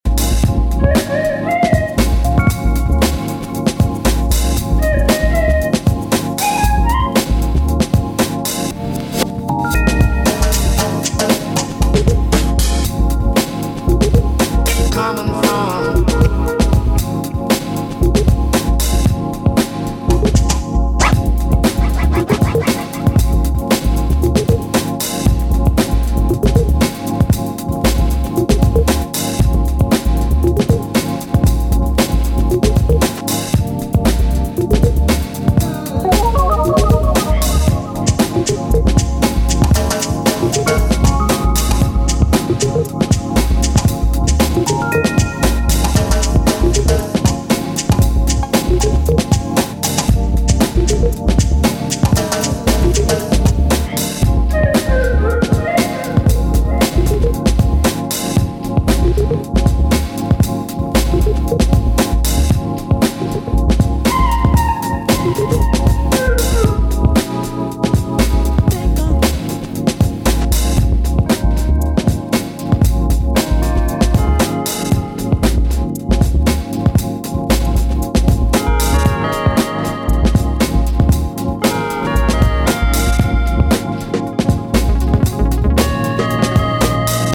cinematic and joyful